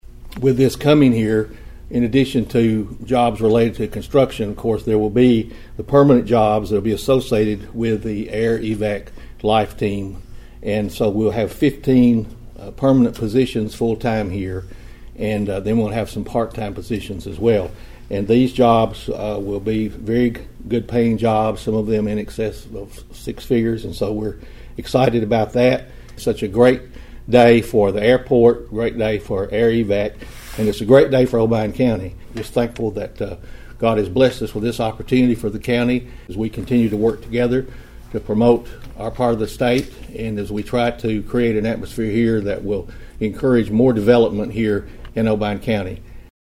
Mayor Carr said the decision by Air Evac will enhance emergency medical assistance and create an economic impact in the area.(AUDIO)